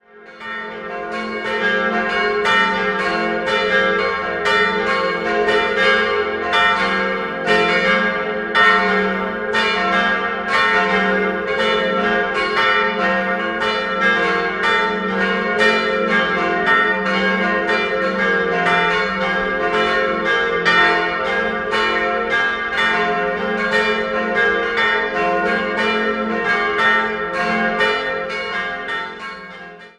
Die mehrfach erweiterte Gnadenkapelle wurde schließlich in den Bau der Wallfahrtskirche integriert. 4-stimmiges Geläute: f'-g'-a'-c'' A lle Glocken wurden 1929 von den Gebrüdern Klaus in Heidingsfeld gegossen.